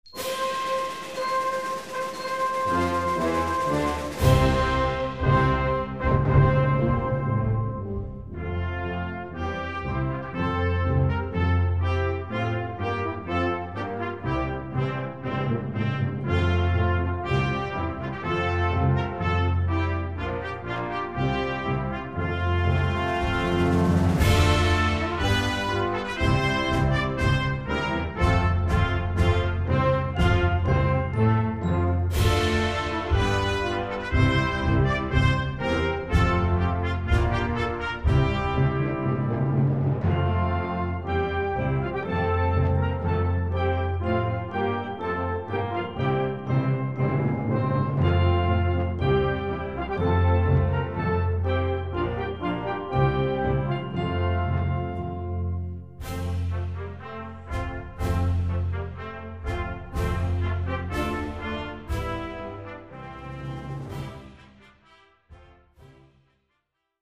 3 Trompettes et Fanfare